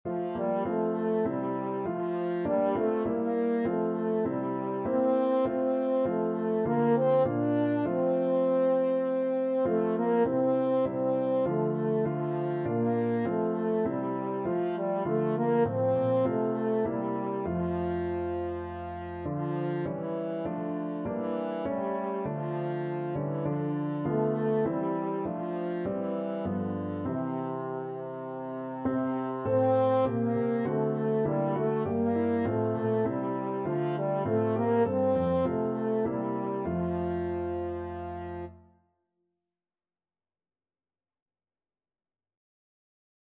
Christmas
4/4 (View more 4/4 Music)
C4-D5
Classical (View more Classical French Horn Music)